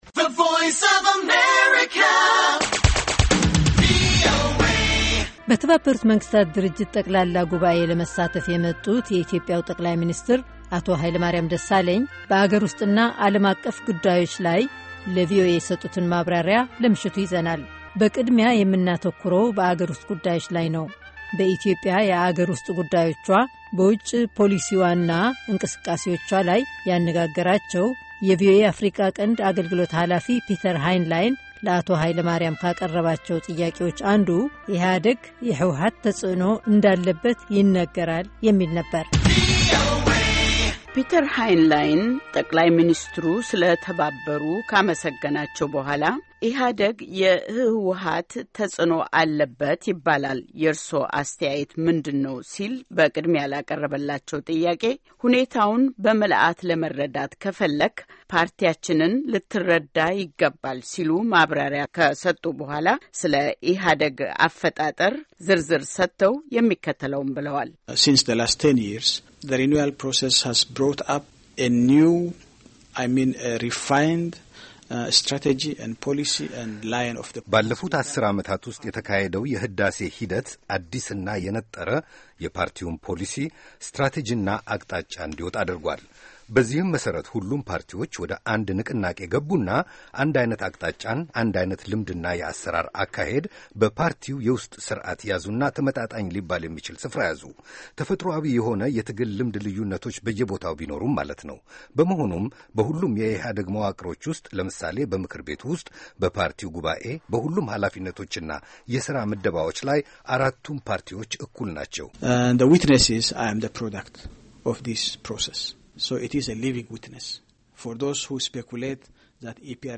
ጠ/ሚ ኃይለማርያም ደሣለኝ ከቪኦኤ ጋር ሙሉ ቃለ-ምልልስ
Translated into Amharic.